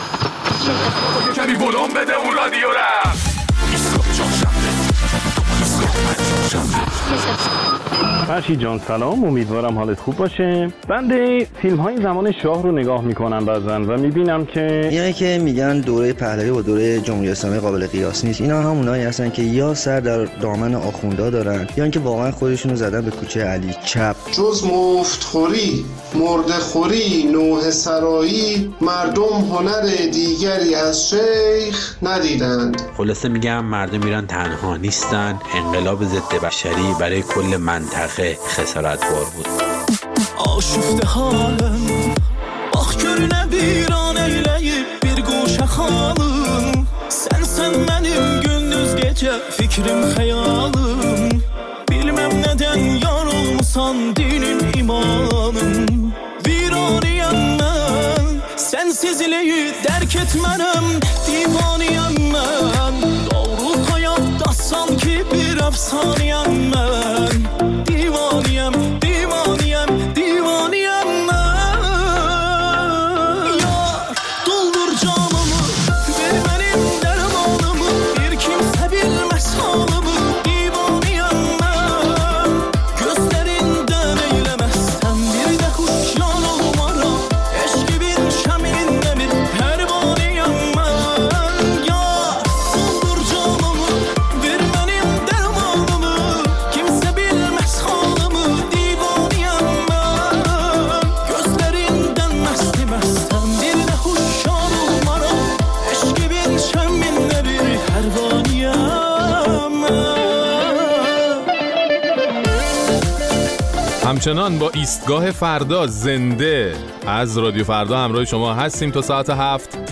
در این برنامه نظرات شما را در مورد نگرانی‌های نظام از گرایش مردم به تغییرات اساسی در حکومت و دیو انگاشته شدن نظام فعلی می‌شنویم.